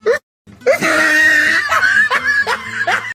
Funny-laughing-sound-effect.mp3